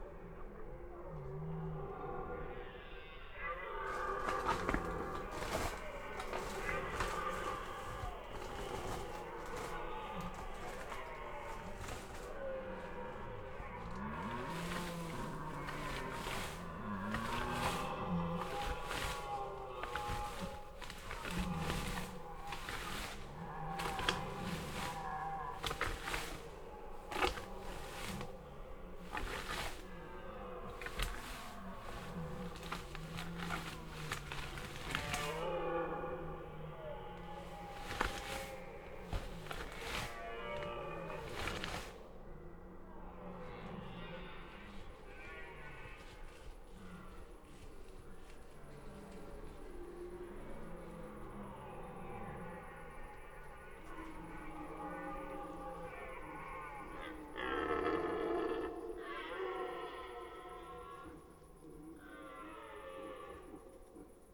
Ce troisième titre de la collection « Galets sonores » regroupe une série d’enregistrements sur le thème du brame du Cerf élaphe, collectés dans différents massifs forestiers de France (Vosges, Loiret, Lozère...) entre 2011 et 2023.